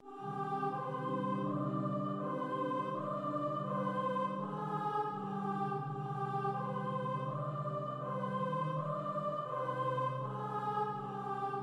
噪声、吱吱声和其他未定义的声波 " 06938 迷幻的低音隆隆声
描述：恐怖低音隆隆声
标签： 迷幻的 可怕的 可怕的 恐惧 SubBass会 隆隆声 黑暗 低音 噪声
声道立体声